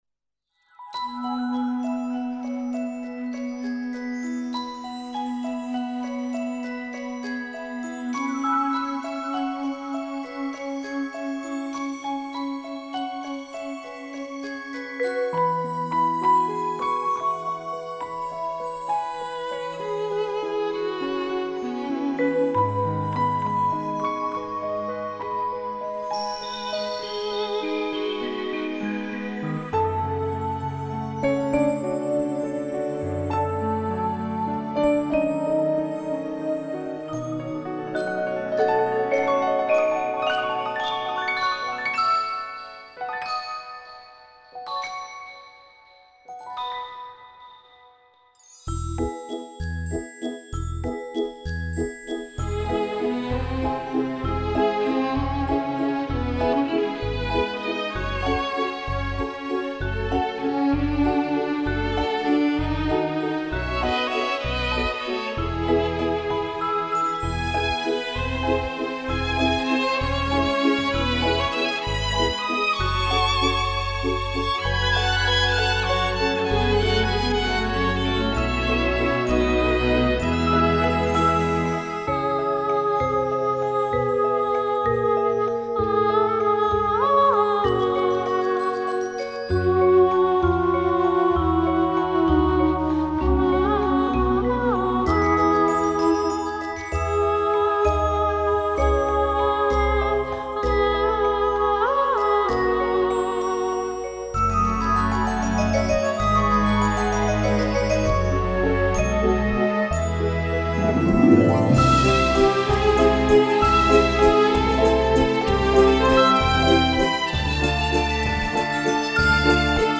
Со вступлением.